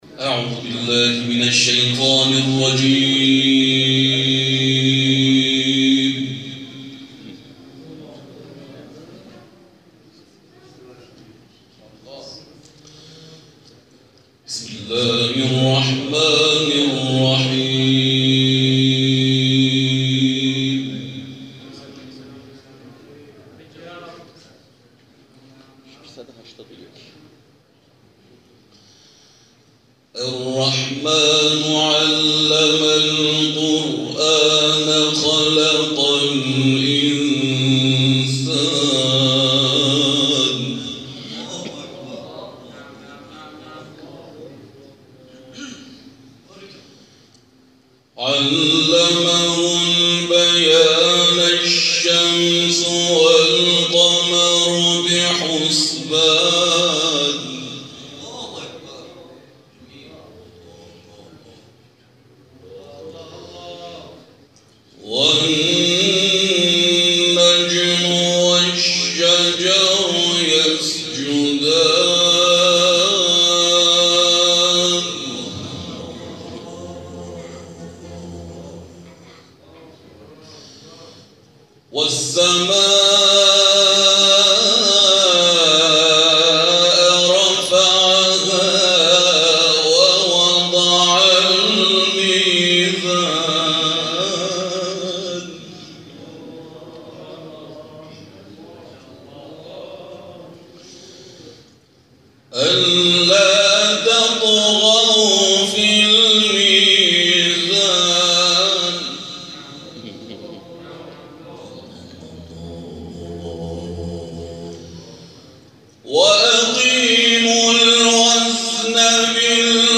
گروه فعالیت‌های قرآنی: دور چهارم مرحله مقدماتی جشنواره تلاوت‌های مجلسی، شب گذشته در حسینیه صاحب‌الزمانی(عج) تهران برگزار شد.
در ادامه تلاوت‌های منتخب این جلسه ارائه می‌شود.